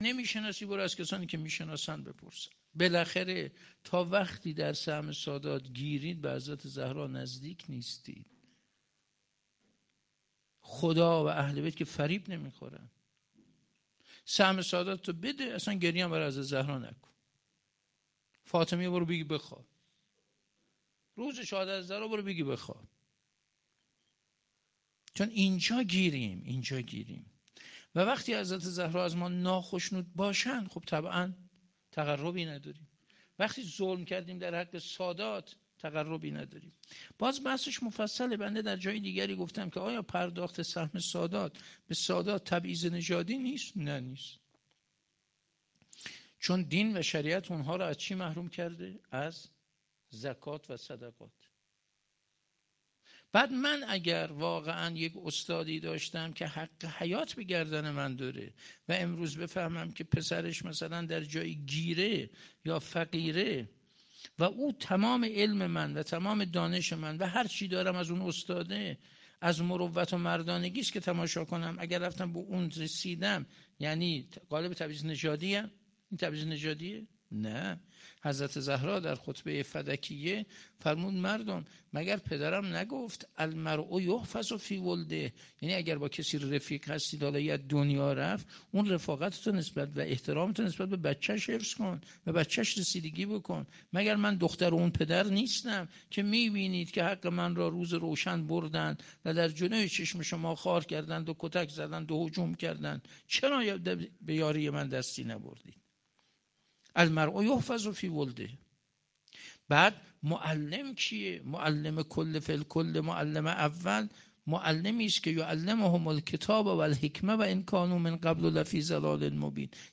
29 دی 97 - سالن همایش های آنلاین - اگه به حق سادات ظلم میکنید فاطمیه اعزاء نگیرید